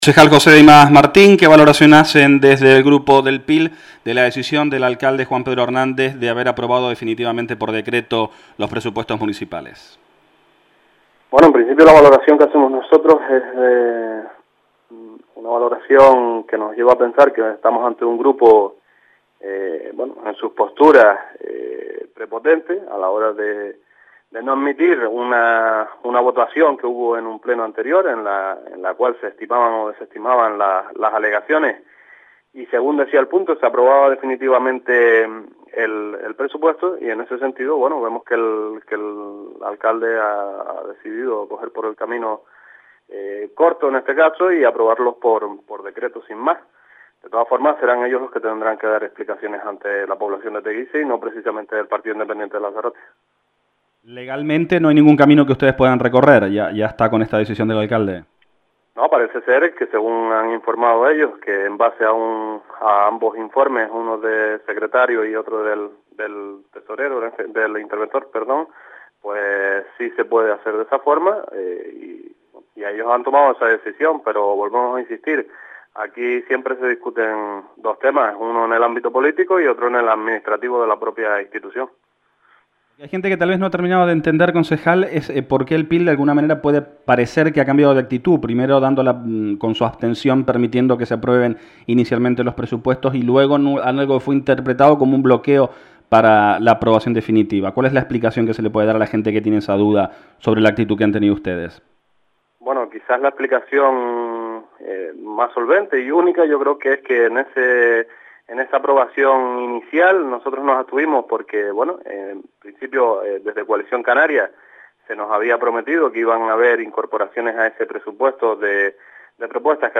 “Parece ser que según han informado Secretaría e Intervención, sí se puede hacer de esa forma y ellos han tomado esa decisión, pero aquí siempre se discuten dos tremas, uno en el ámbito político y otro en el administrativo”, señaló el concejal del PIL, José Dimas Martín, en declaraciones a Lancelot Digital.